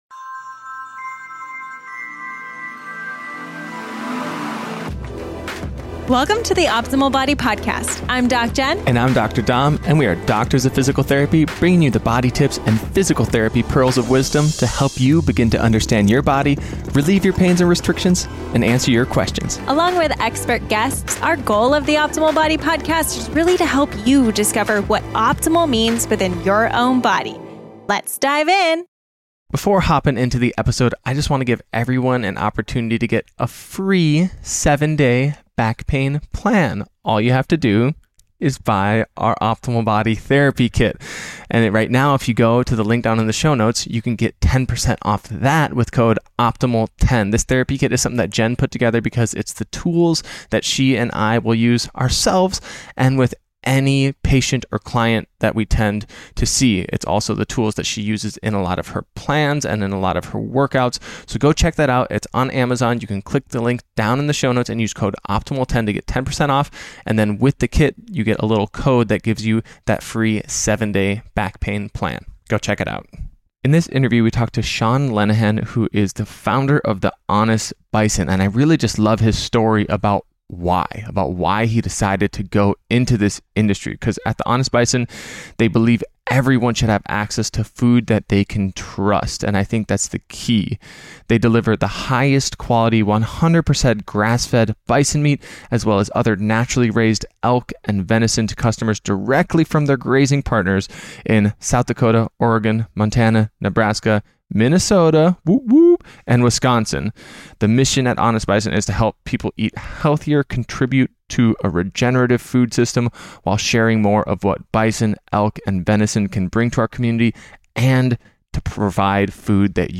What You Will Learn in this Interview